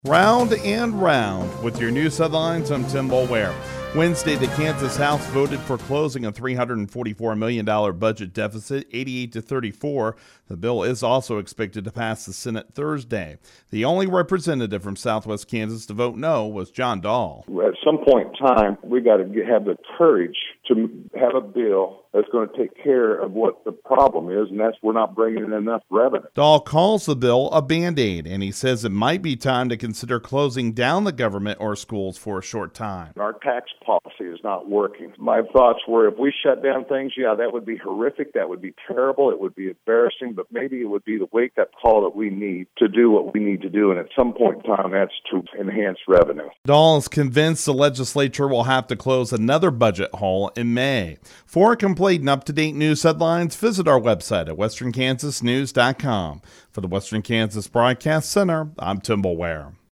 *On-air story*